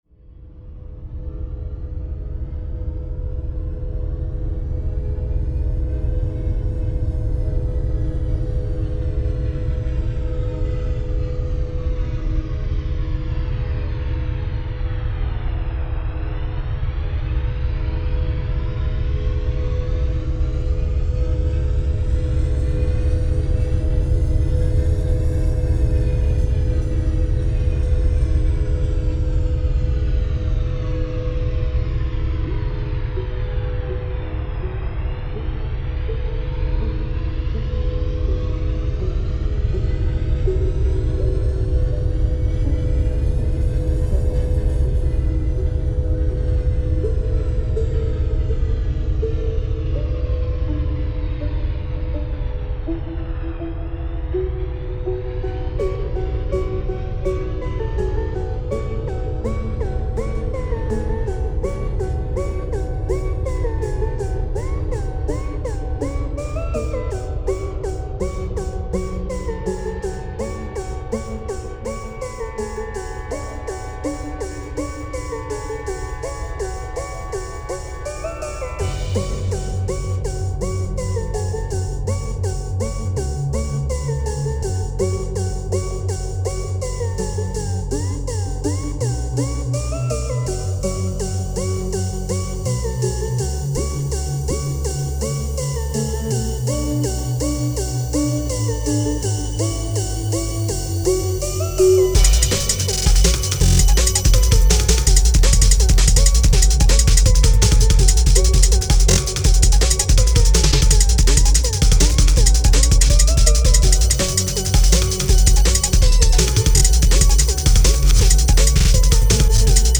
These are some of my old beats.